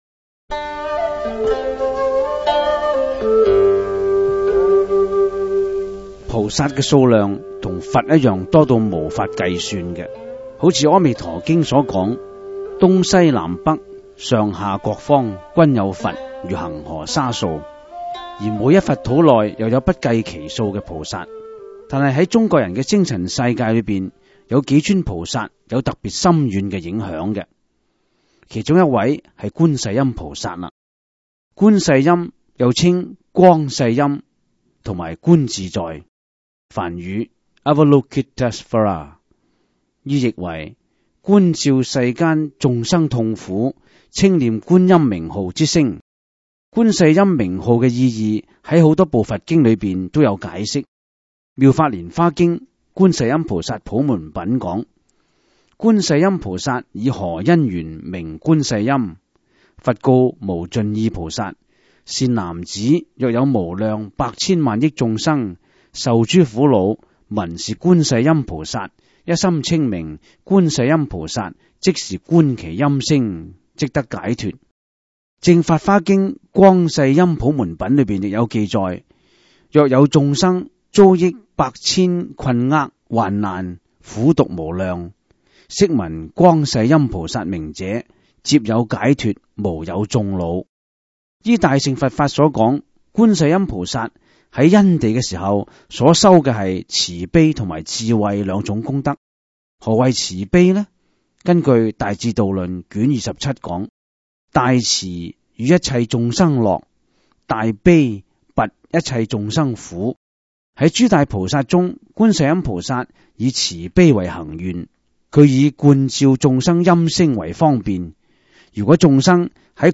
第 十 五 辑       (粤语主讲  MP3 格式)